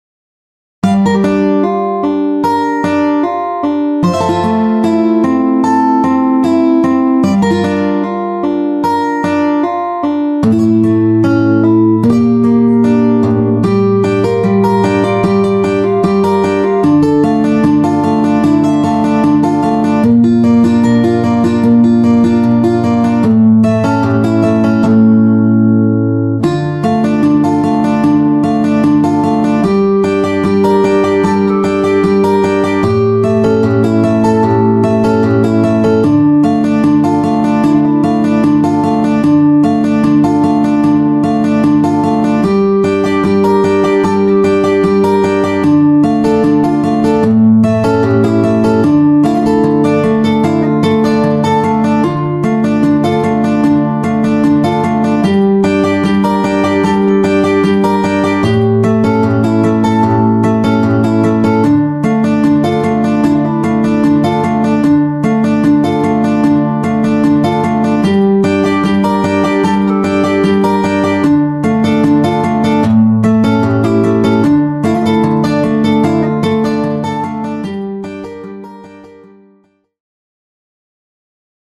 T Guitar